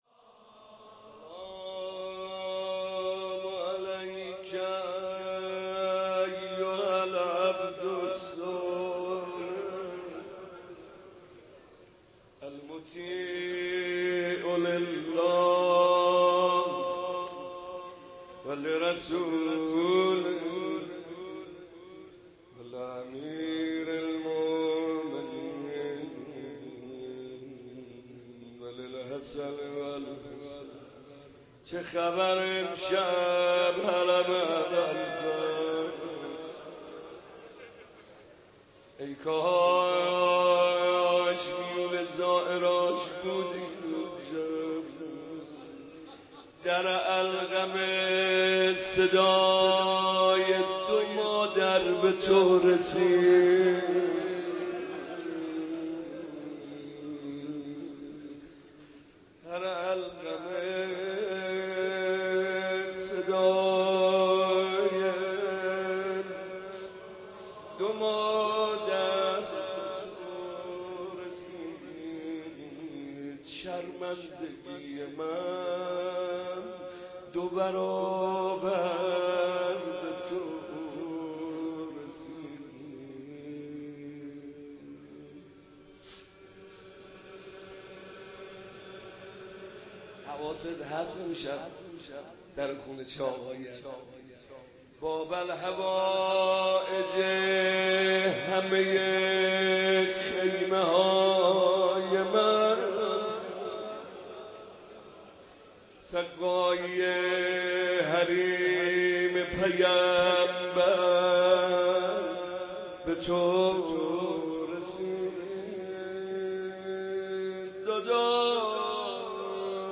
متن روضه حضرت عباس (ع)